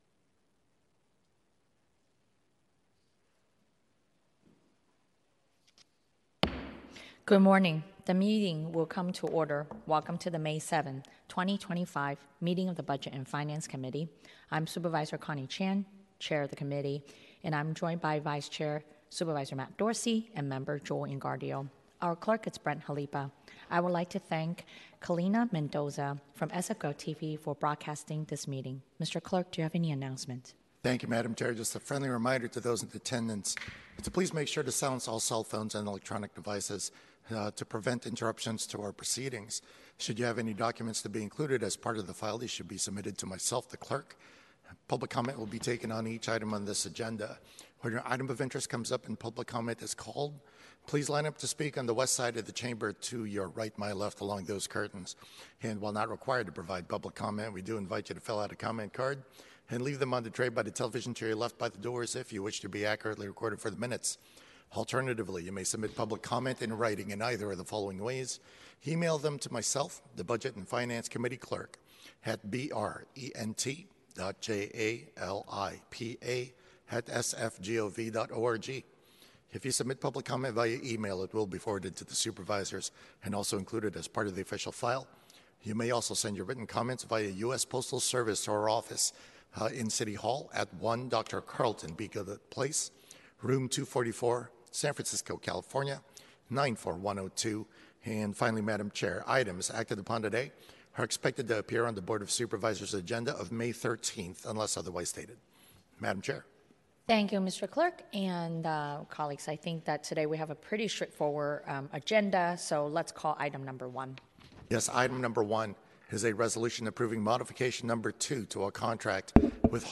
Budget and Finance Committee - Regular Meeting - May 07, 2025